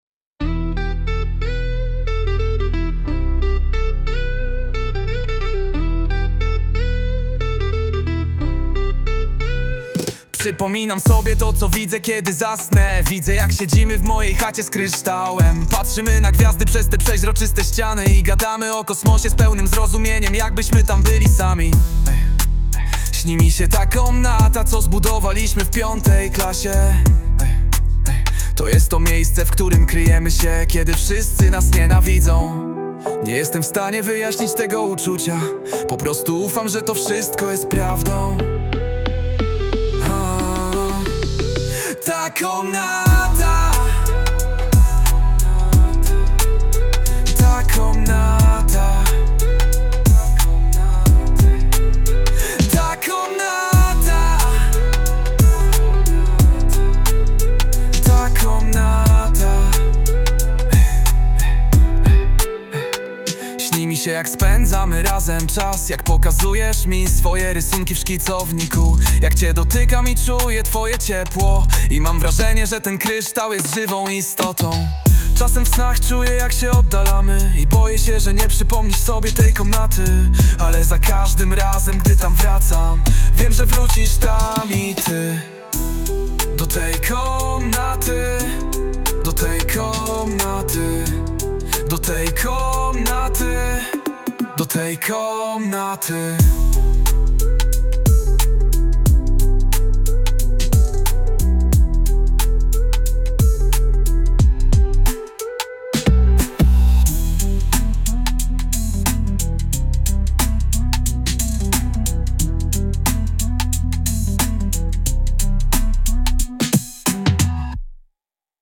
Ma chłopak talent do generowania piosenek ai.